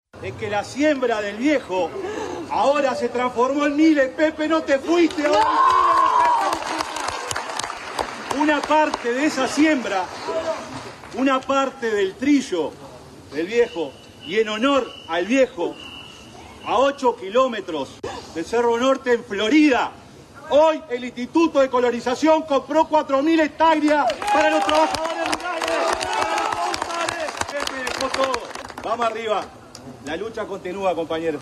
En la sede del MPP, al detenerse el cortejo, el secretario de la Presidencia de la República, Alejandro Sánchez realizó “una arenga”, y expresó: “la siembra del Viejo ahora se transformó en miles. Pepe, no te fuiste”